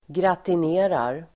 Ladda ner uttalet
Folkets service: gratinera gratinera verb, gratinate , bake in a gratin dish Uttal: [gratin'e:rar] Böjningar: gratinerade, gratinerat, gratinera, gratinerar Definition: bryna i ugn (brown in the oven)
gratinerar.mp3